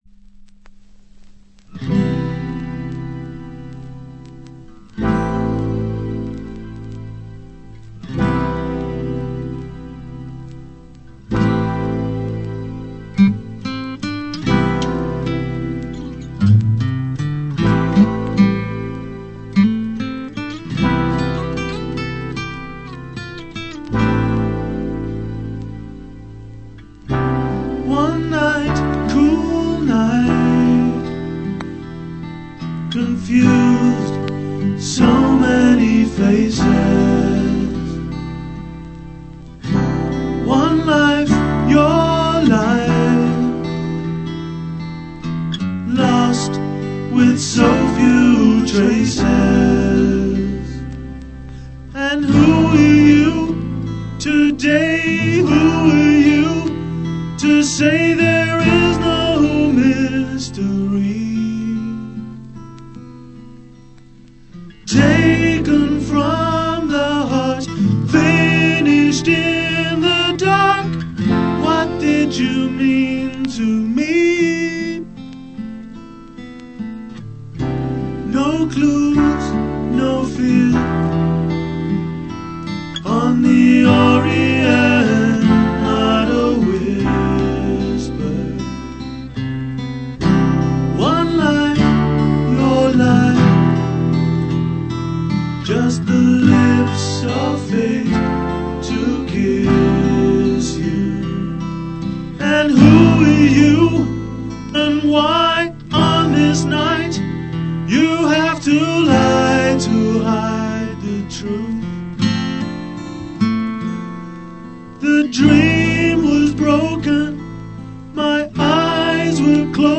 Genre: POP / CLASSICAL / ROCK ETC